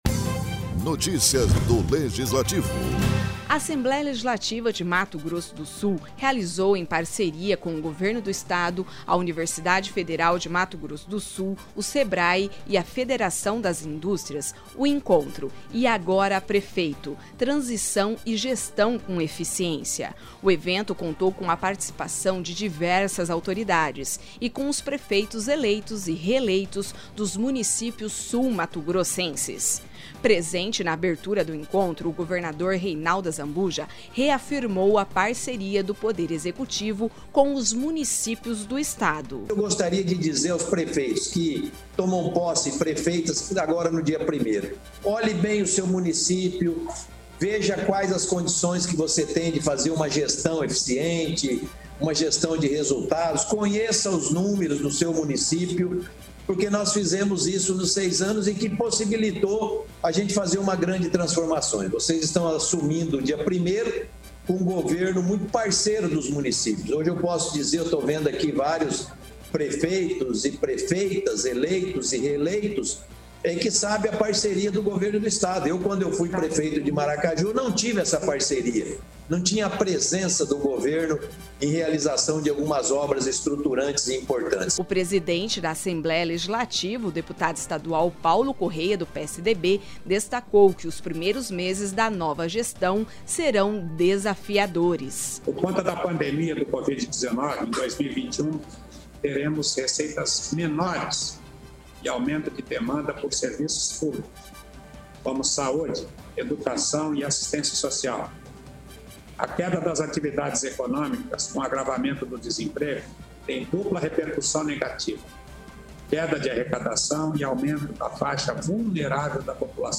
Produção e locução: